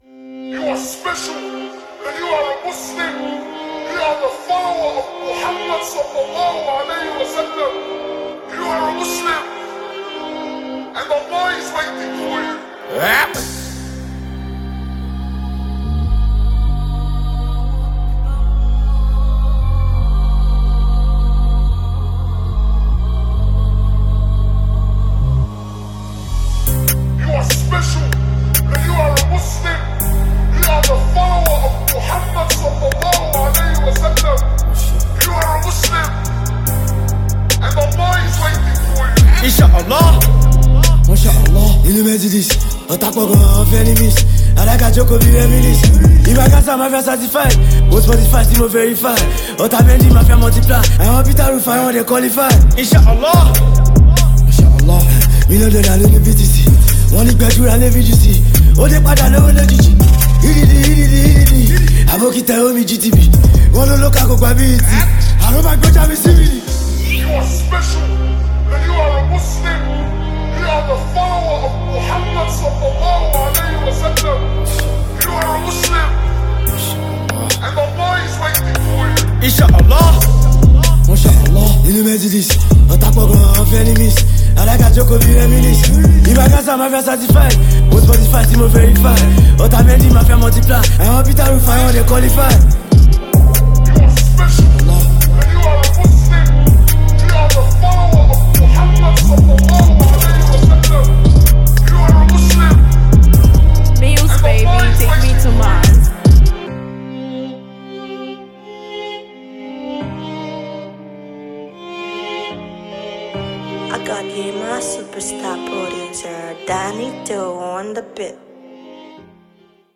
Nigerian rap sensation
With its enchanting melodies and infectious beats